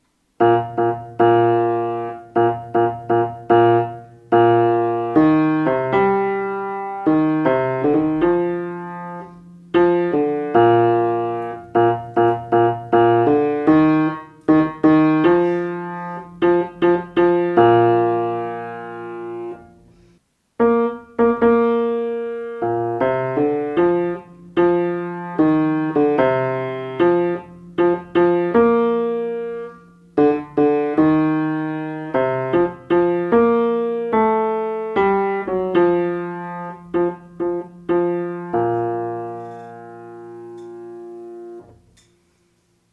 basse
Kalon_chlan_basse.mp3